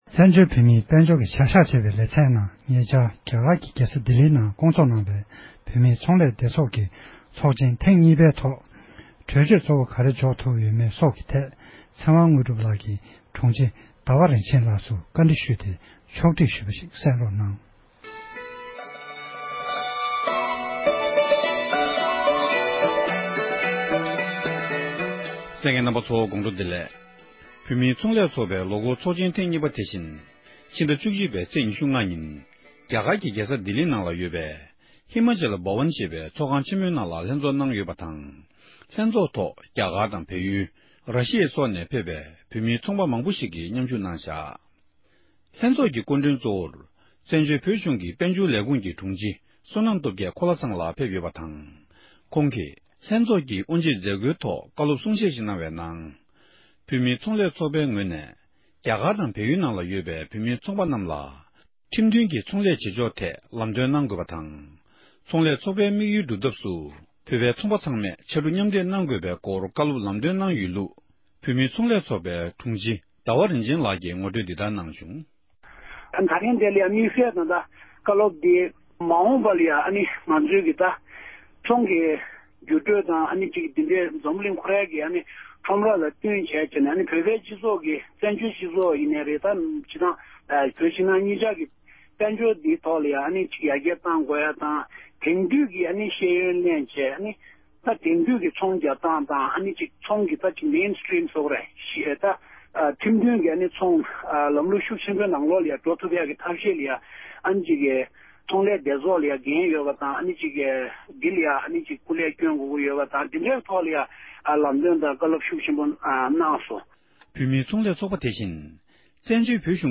བཀའ་འདྲི